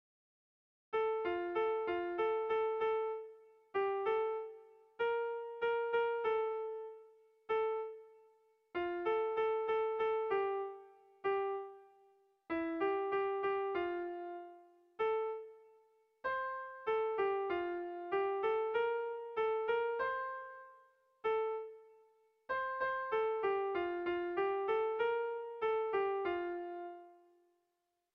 Bertso melodies - View details   To know more about this section
Dantzakoa
AB